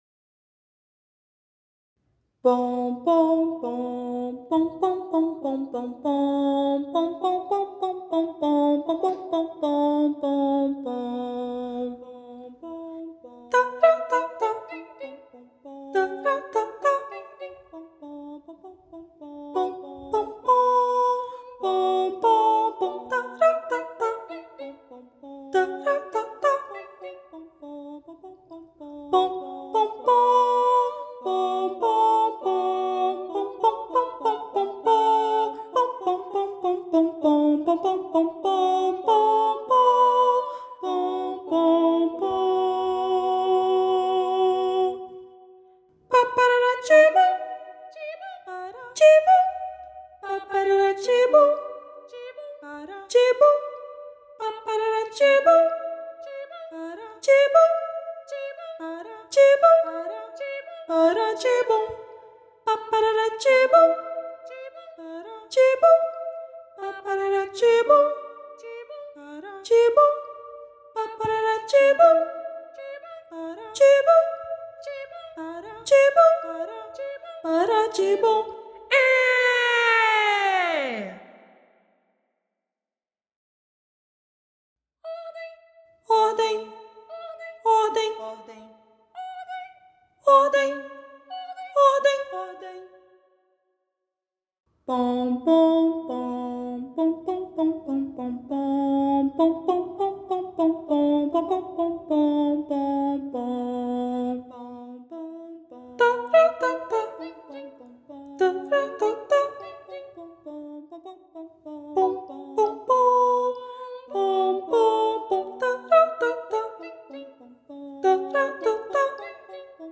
“3 Canções para os pequeninos” é um conjunto de três pequenas peças para coro infantil a três vozes.
Na segunda canção (Acalanto), Rafael Bezerra traz uma singela canção de ninar, com texto próprio, que pode ser vista como uma oração de proteção, enquanto na terceira canção (Marcha), podemos sentir o vigor das bandas militares não apenas pela rítmica utilizada como também pelo uso de onomatopeias que nos transportam para esse universo.
Voz Guia 2